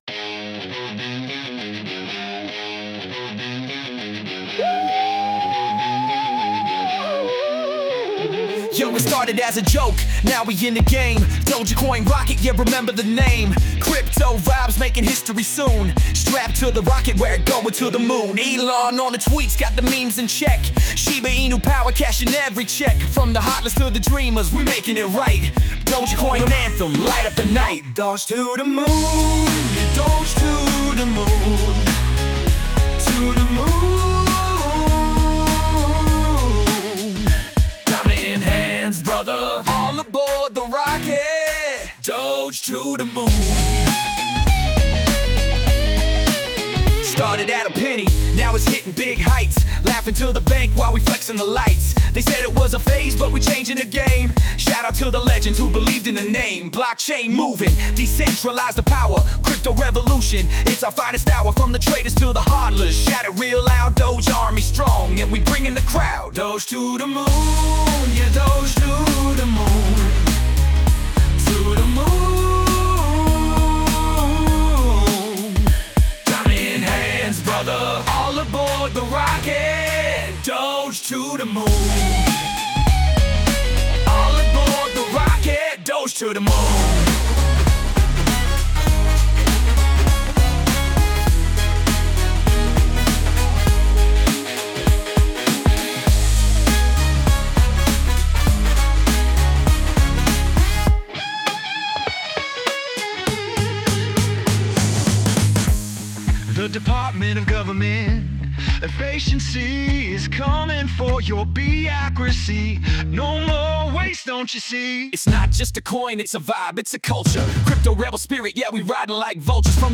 Someone sent me an AI-generated Dogecoin anthem: To Da Moon.